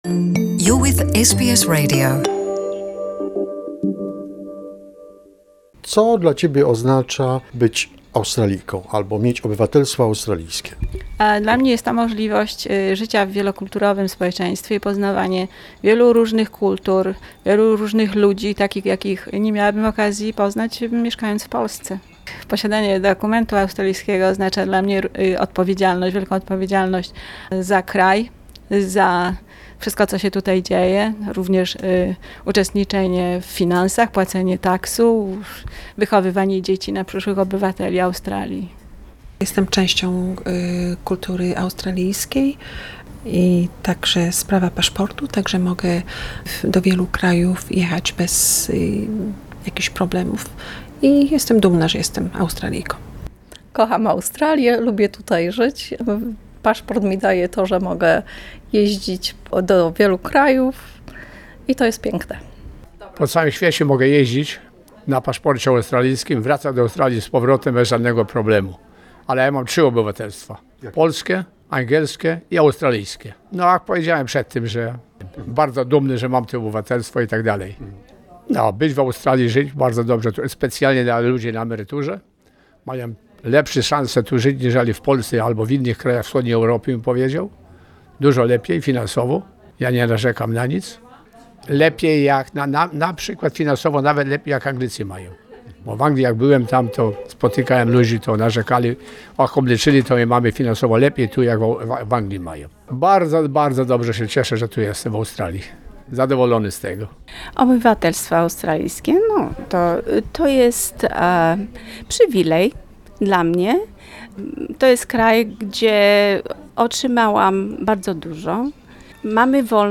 Australian Citizenship: Vox Pop
What does it mean to have an Australian citizenship ? Polish listeners share their opinions.